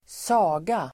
Uttal: [²s'a:ga]